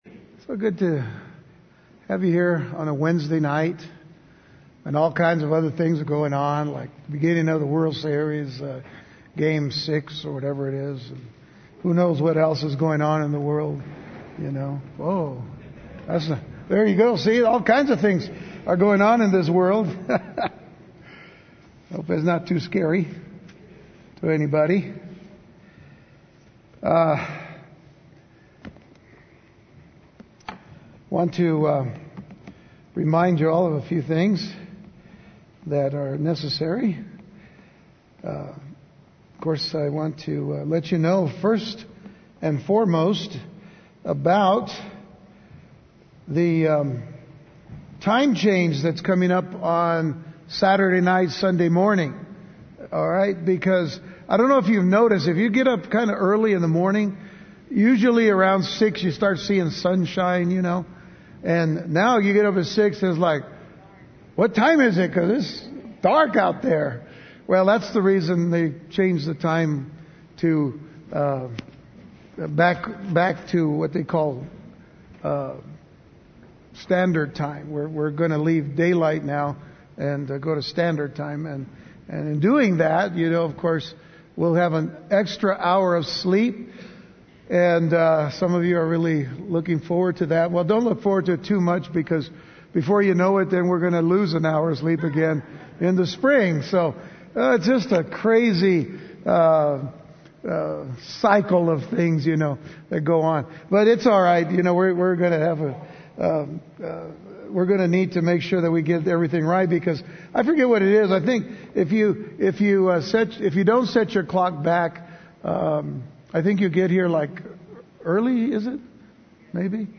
Index of /Sermons/Revelation